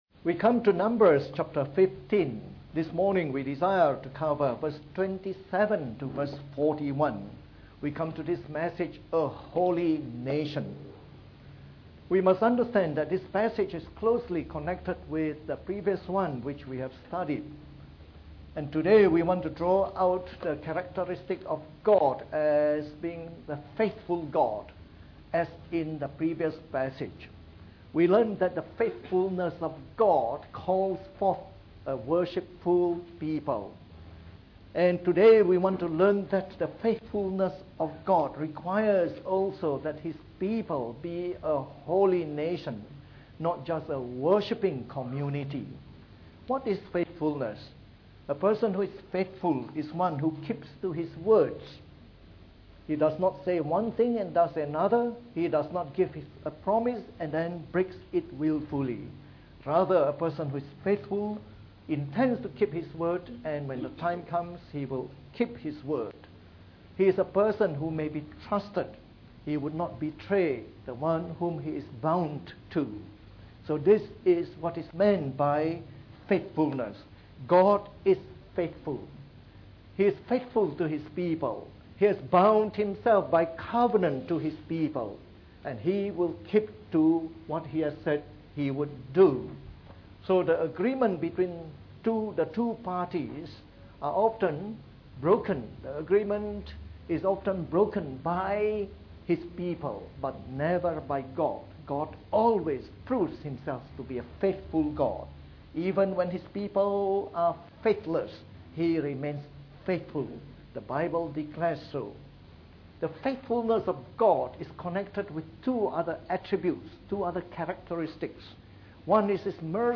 From our series on the “Book of Numbers” delivered in the Morning Service.
Apologies for the diminished audio quality of this recording.